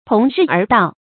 同日而道 tóng rì ér dào
同日而道发音
成语注音 ㄊㄨㄙˊ ㄖㄧˋ ㄦˊ ㄉㄠˋ